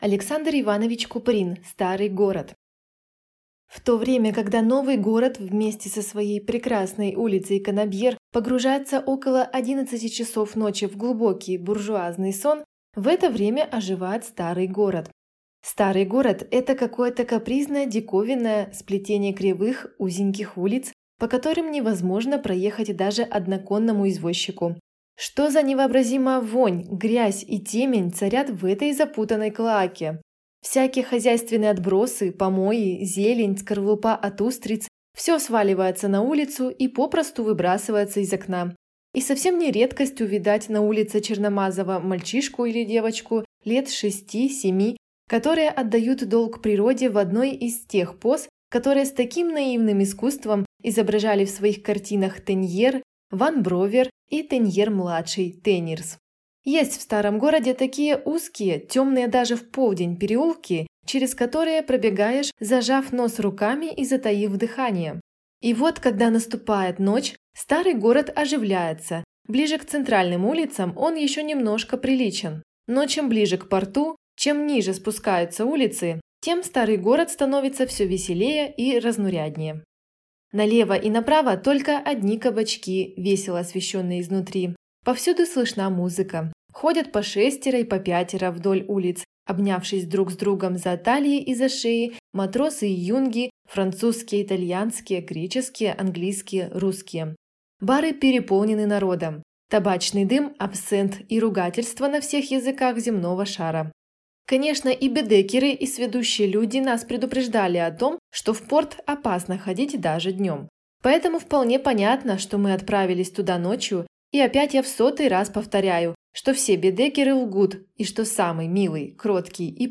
Аудиокнига Старый город | Библиотека аудиокниг
Aудиокнига Старый город Автор Александр Куприн Читает аудиокнигу Екатерина Гусева.